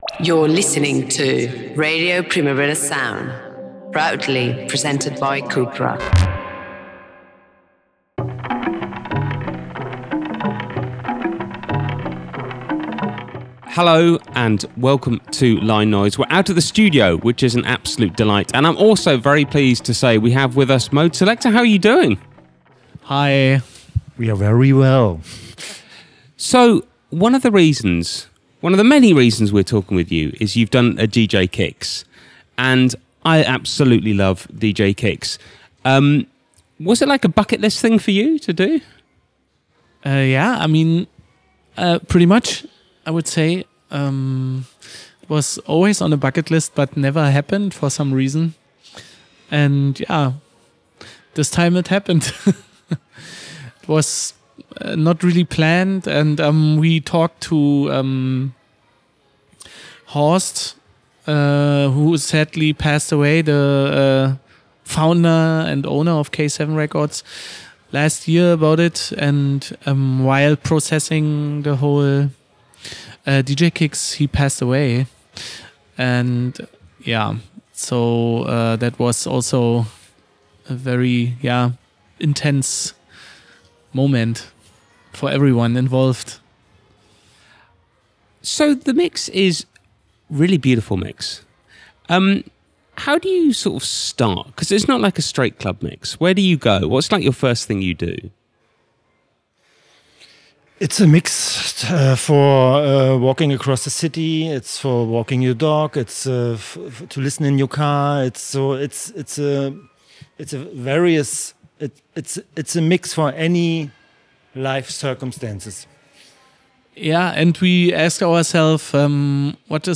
I spoke to him ahead of his Barcelona gig, about two years of change, switching instruments, emotional strain and the power of an intro.